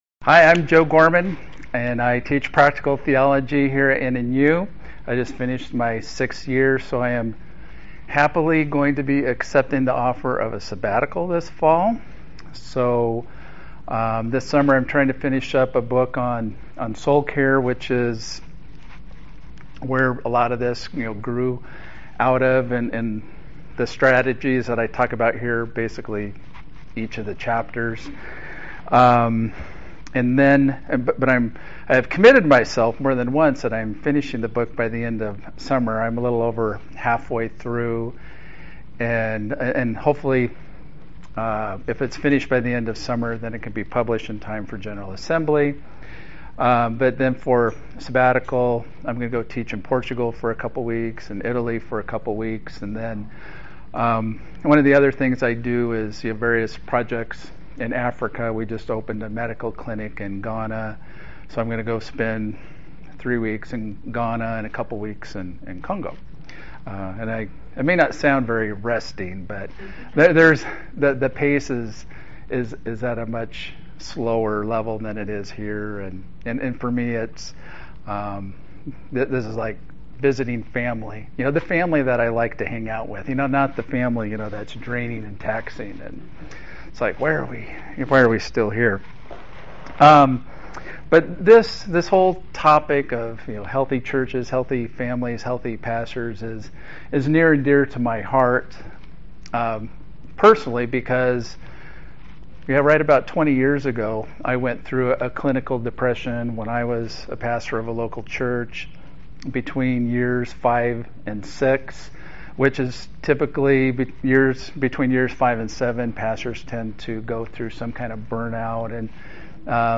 This seminar seeks to nurture healthy churches, healthy families, and healthy pastors.